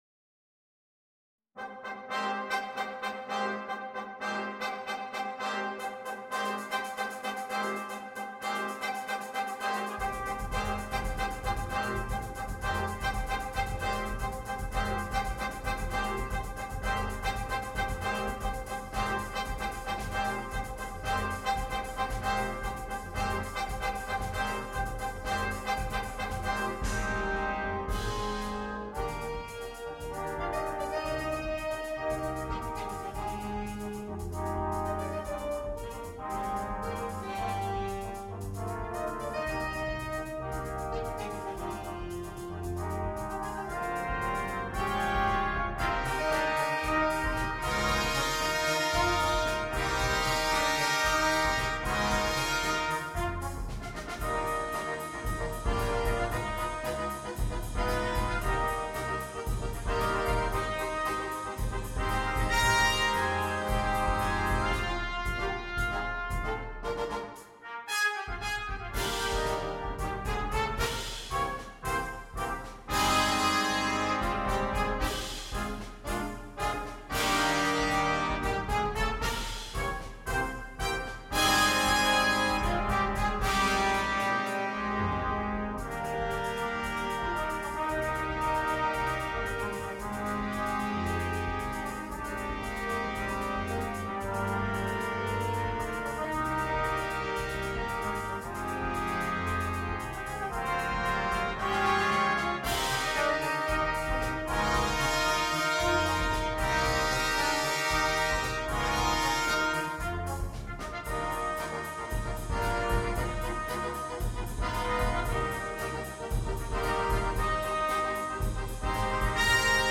на биг-бэнд